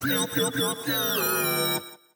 气喇叭似的哔哔声